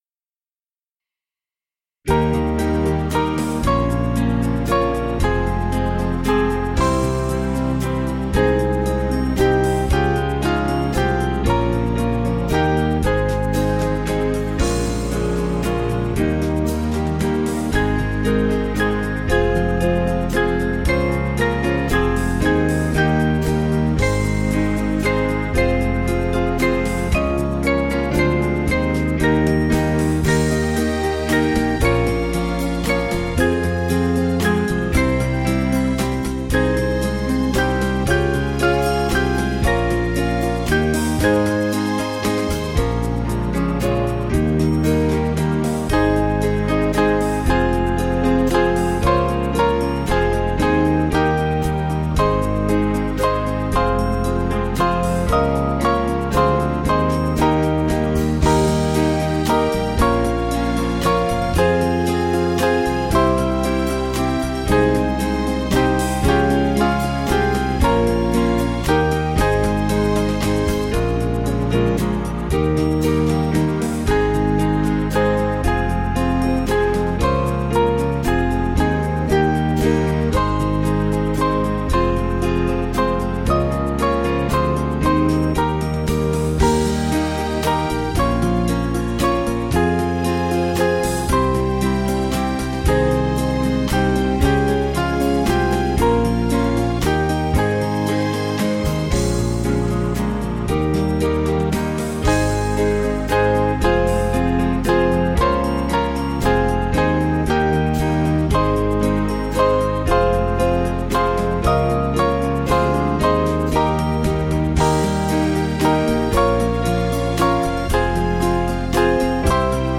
Small Band
(CM)   6/Ab 491.1kb